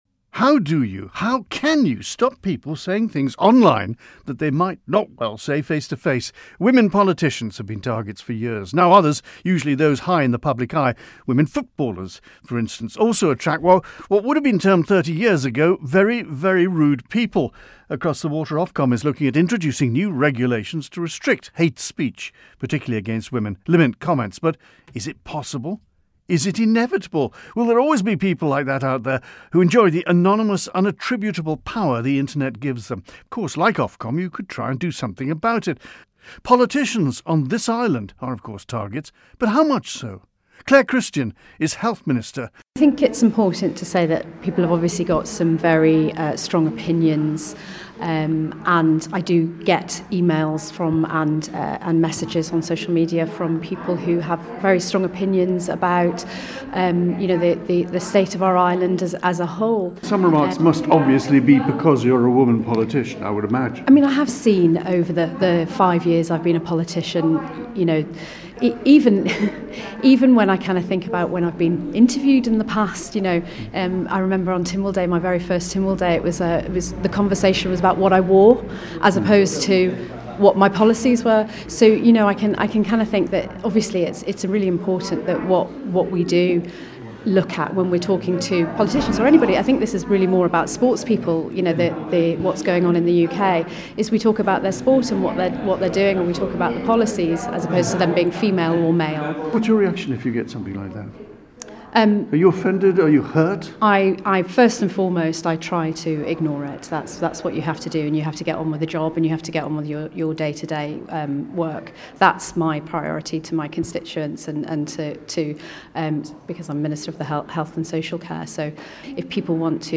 Ms Christian is the Health Minister, and her department has attracted a great deal of public comment.
She says she tends to put any offensive remarks to one side: